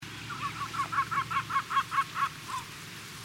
Large Gulls in the Southeastern Urals
Call recording 3